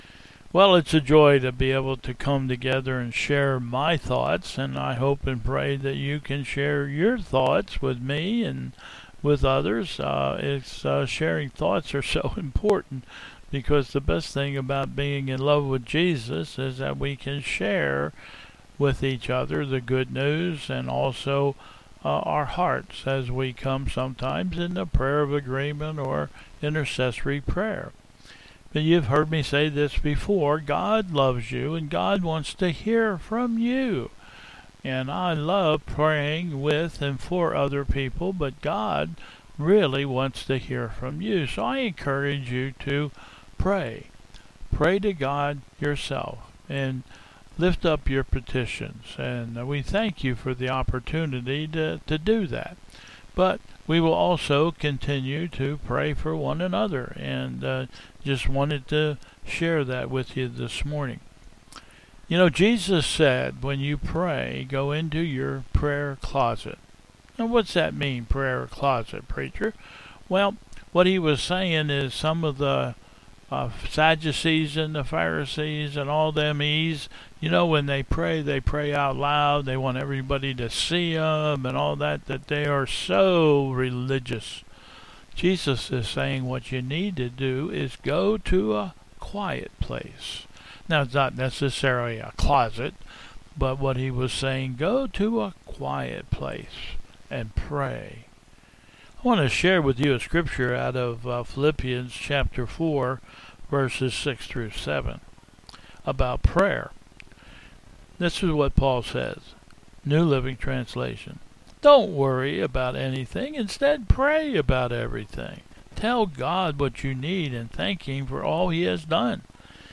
Processional